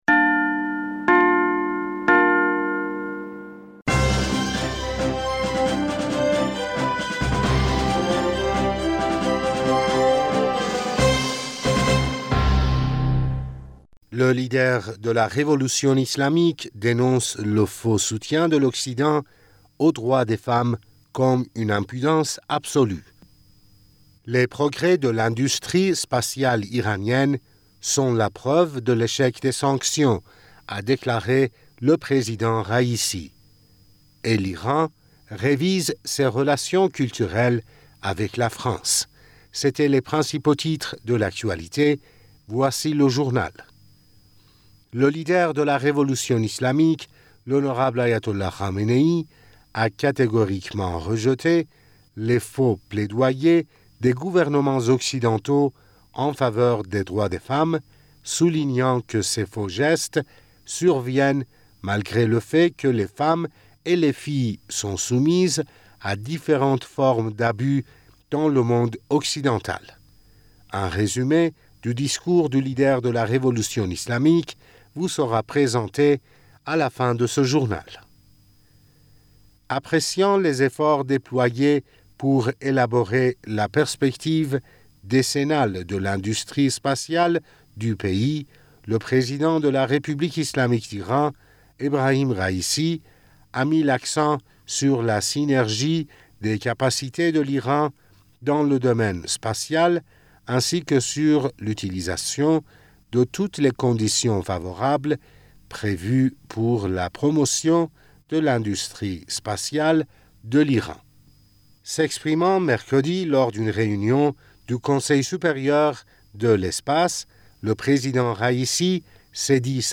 Bulletin d'information du 05 Janvier